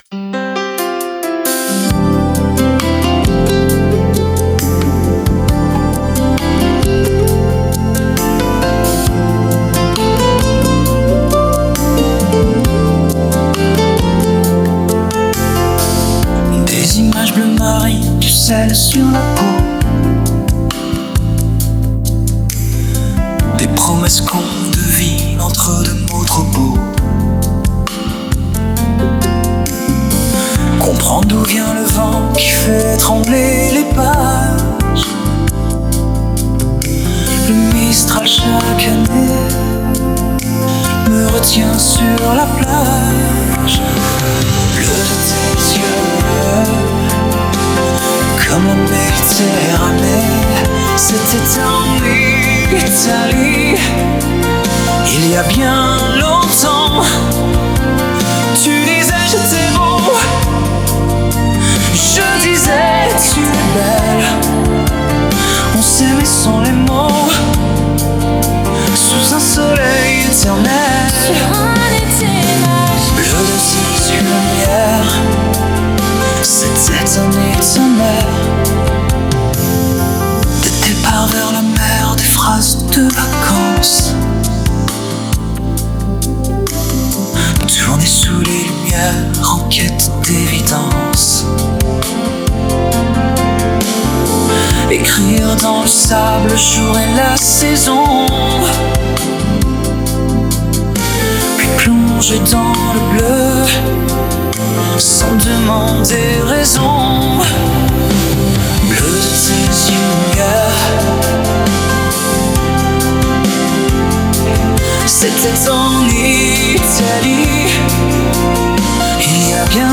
chanson d’amour douce et nostalgique